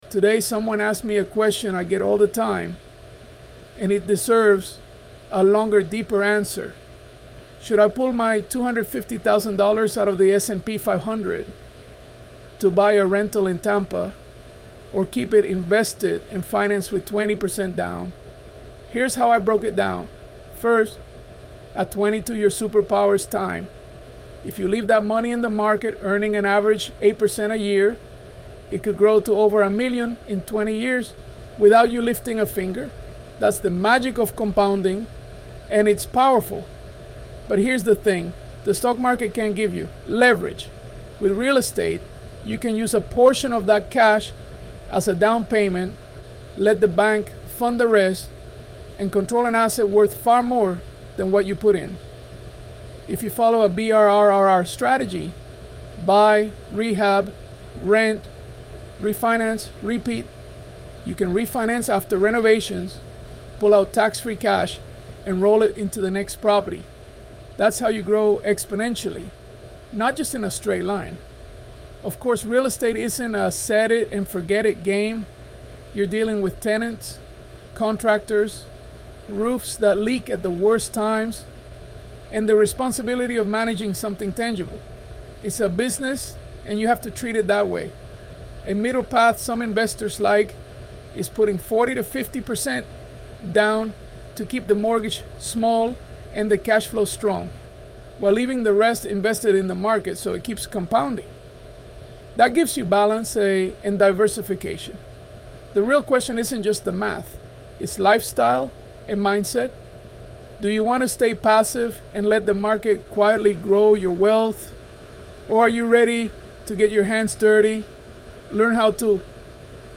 ElevenLabs_Untitled_project-24.mp3